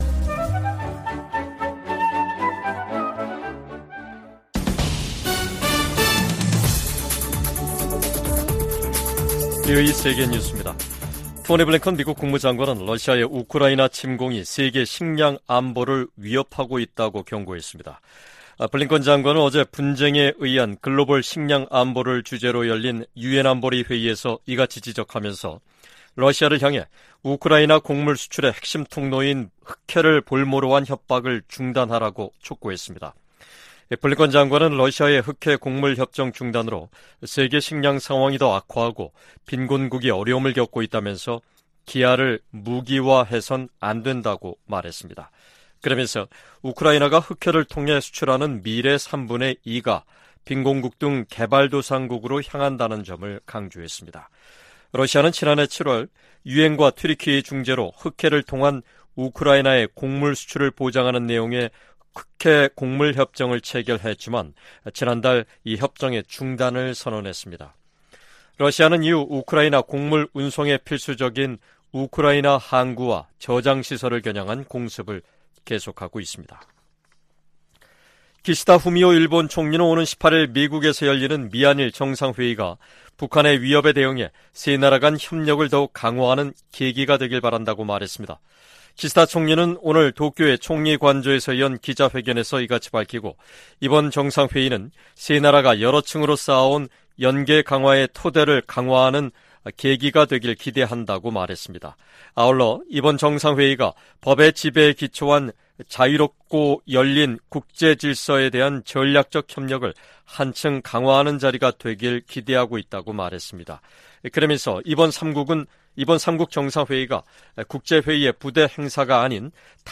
VOA 한국어 간판 뉴스 프로그램 '뉴스 투데이', 2023년 8월 4일 3부 방송입니다. 오는 18일 미한일 정상회의에서 북한의 미사일 방어 등 3국 안보 협력을 강화하는 방안이 논의될 것이라고 한국 국가안보실장이 밝혔습니다. 러시아 국방장관의 최근 평양 방문은 군사장비를 계속 획득하기 위한 것이라고 백악관 고위관리가 지적했습니다. 토니 블링컨 미 국무장관은 북한이 월북 미군의 행방과 안위등에 관해 답변을 하지 않았다고 말했습니다.